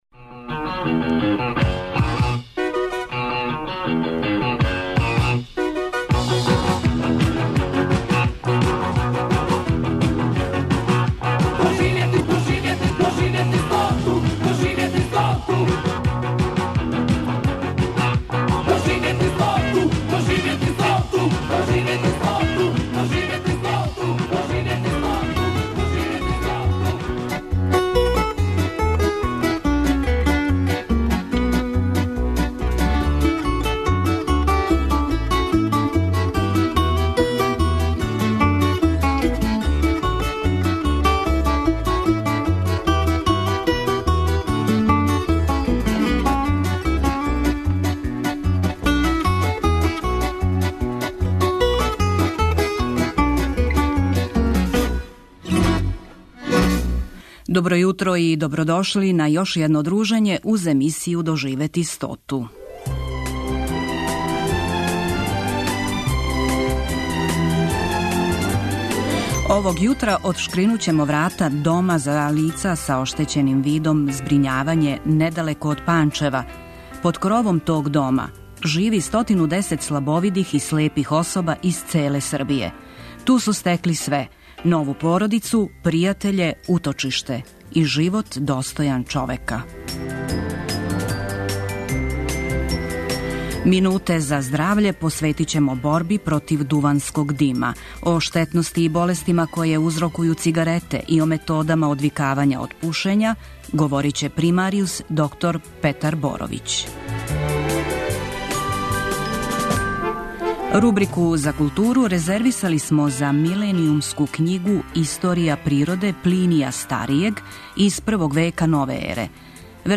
Емисија "Доживети стоту" Првог програма Радио Београда доноси интервјуе и репортаже посвећене старијој популацији.